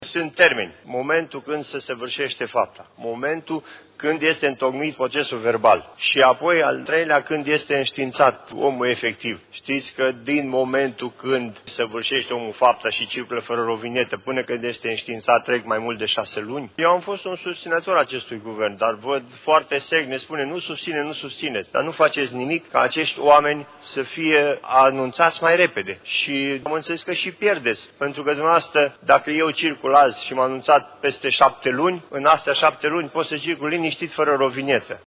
În timpul dezbaterilor, senatorul independent Ioan Iovescu a atras atenția că, în prezent, trece mai mult de jumătate de an până când șoferul e înștiințat că a fost depistat fără taxa de drum valabilă.